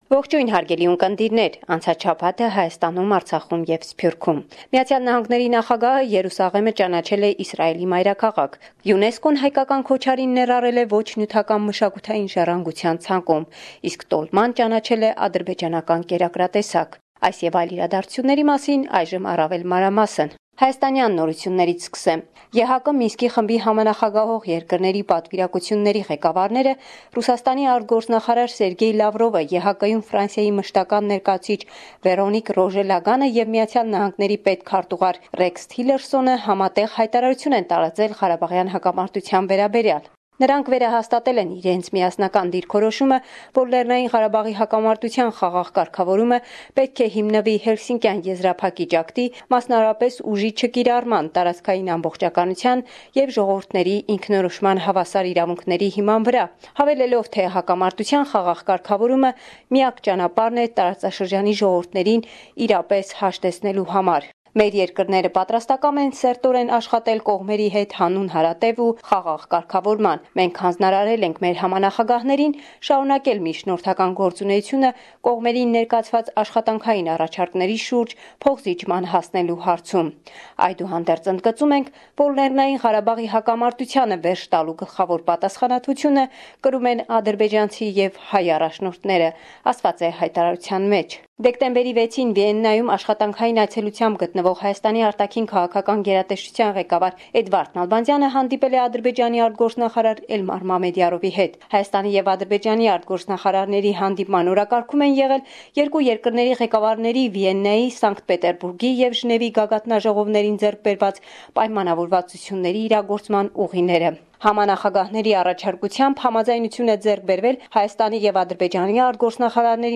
Վերջին Լուրերը – 12 Դեկտեմբեր, 2017